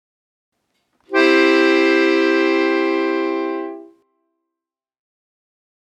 Un acorde é un conxunto de 3 notas que soan á vez, e van a distancia de 3ª.
Acorde
acorde.mp3